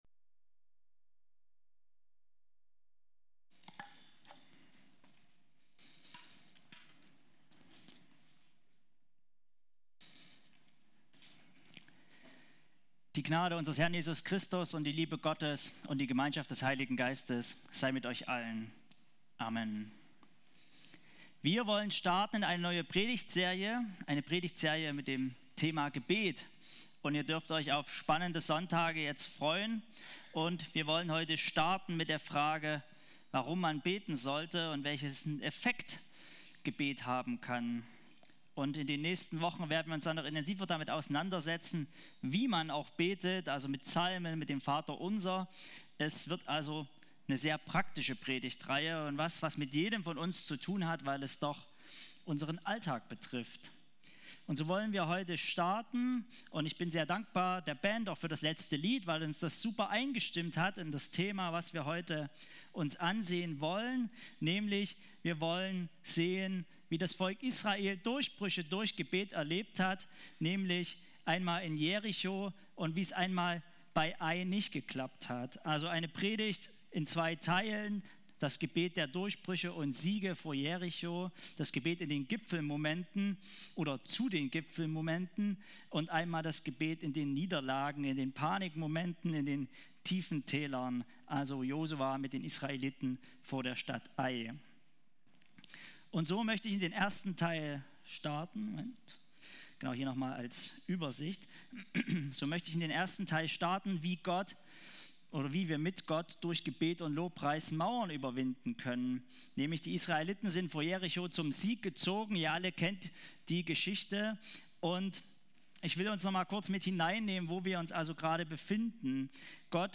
2025-10-19_Predigt.mp3